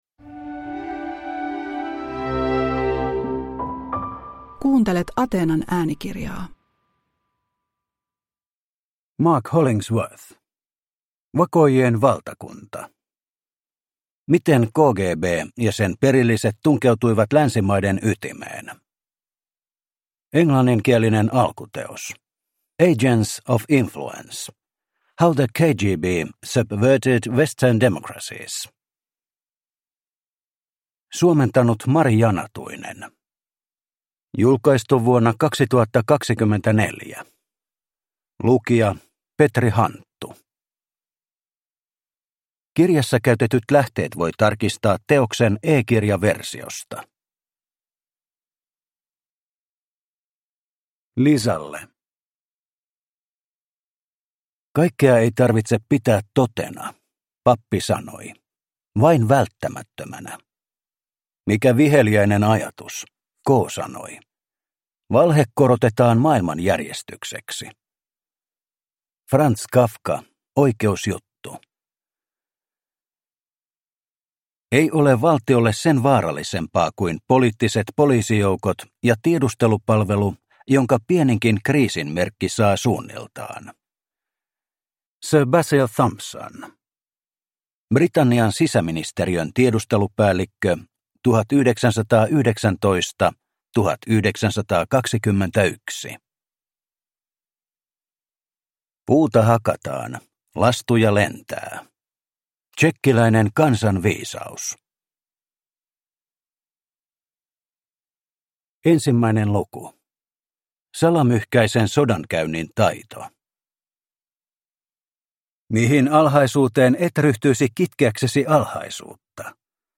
Vakoojien valtakunta – Ljudbok